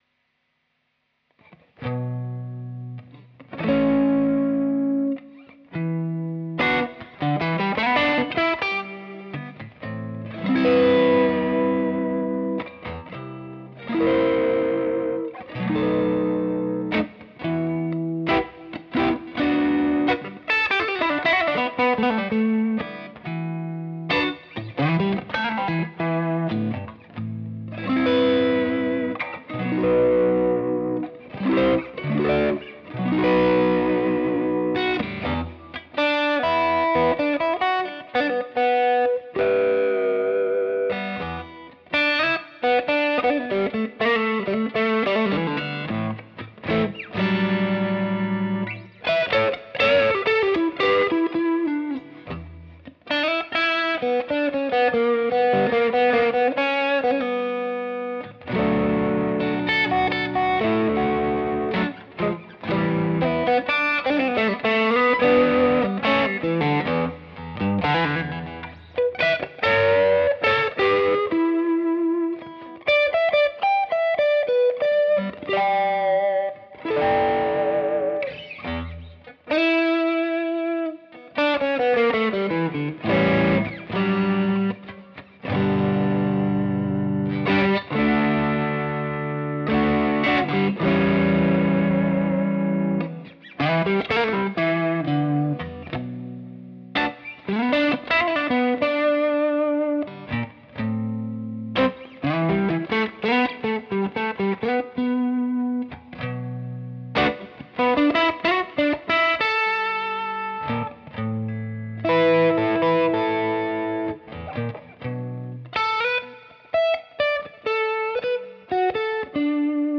Je suis revenu à un ampli et des pédales après un Helix.
En fin de compte ça marche bien sans trop de souffle (très peu même si c'est toujours un peu plus qu'un Helix).
wos_cab_deluxe_reverb_et_caline_tube_screamer.mp3